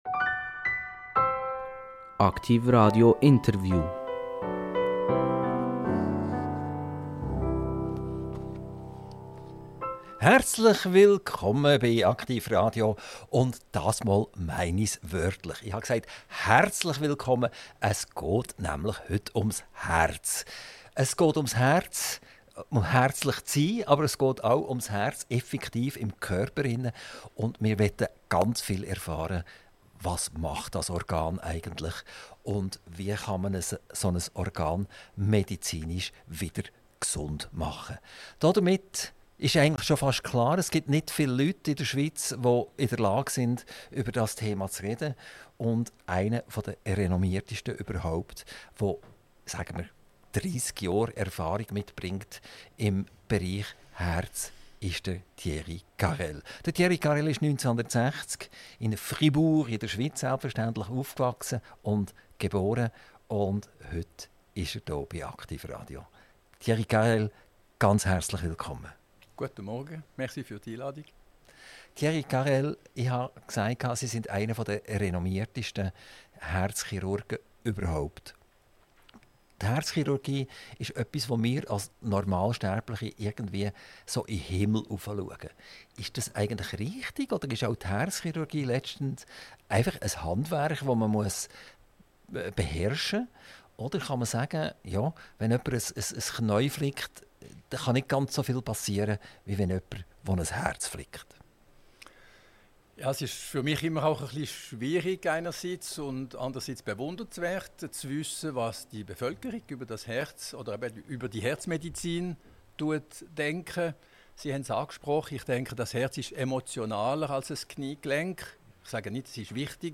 INTERVIEW - Prof. Dr. Thierry Carrel - 28.08.2025 ~ AKTIV RADIO Podcast